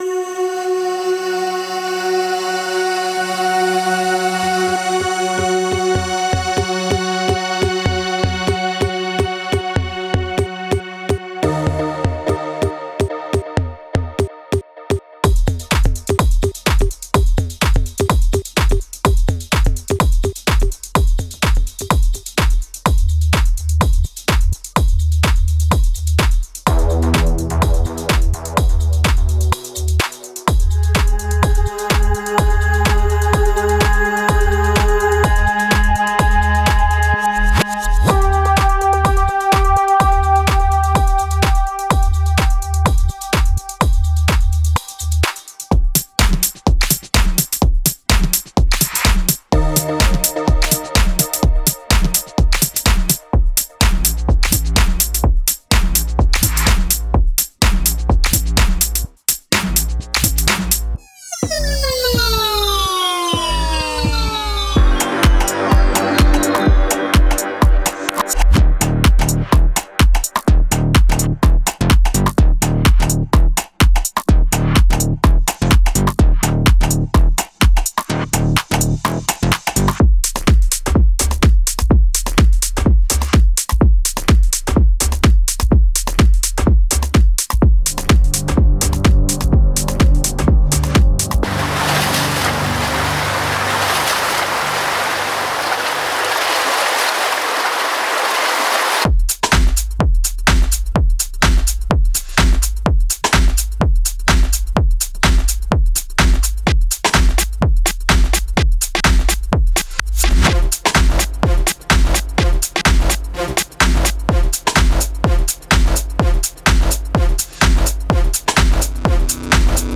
Genre:House
アナログ感あふれるサウンドで新しい音楽制作の可能性を切り開くマイクロハウスパックです。